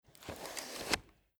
Switch on the camera
58207_Kamera_anschalten.mp3